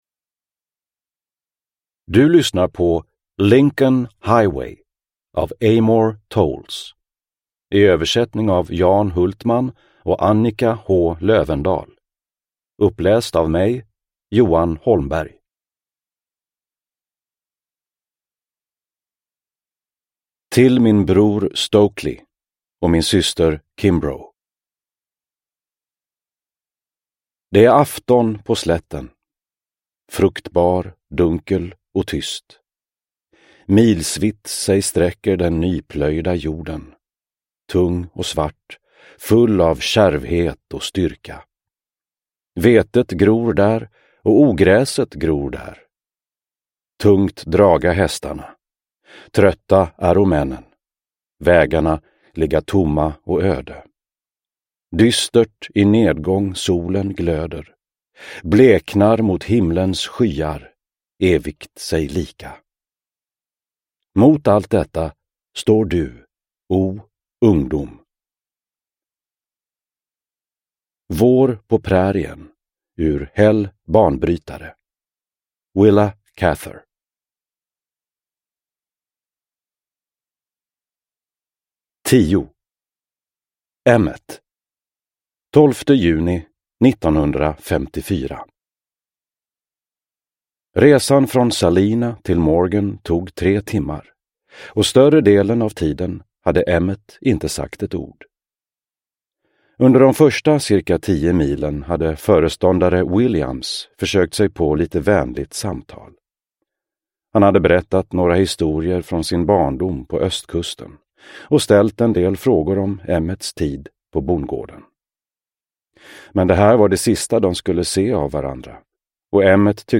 Lincoln Highway – Ljudbok – Laddas ner